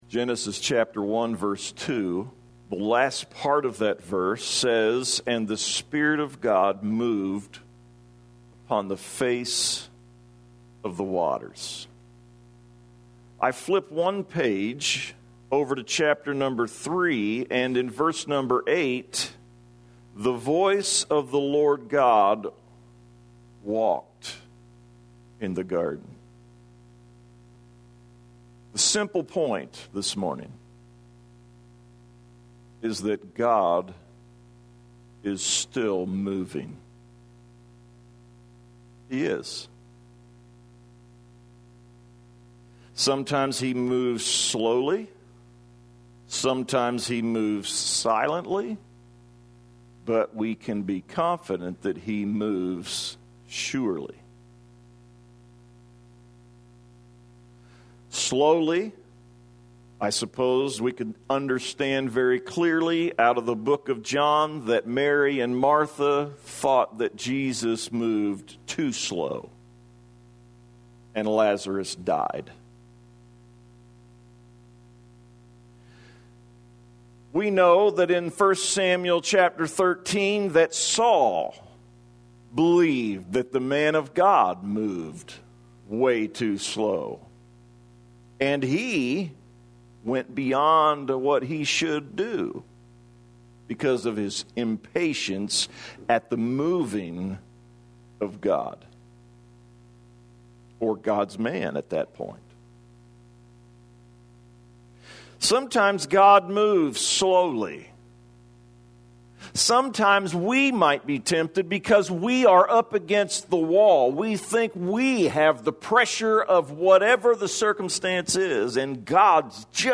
A sermon entitled “He Still Moves”